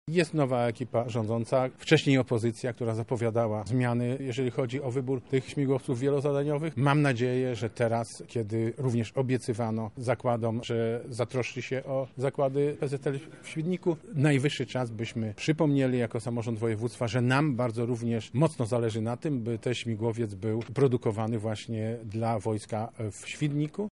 – mówi marszałek województwa Sławomir Sosnowski.